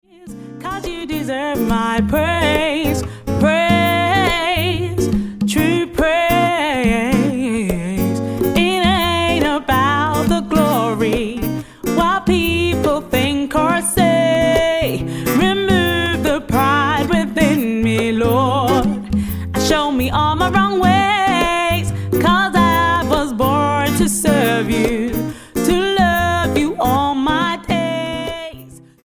STYLE: R&B
is gently simmering rather than volcanic hot.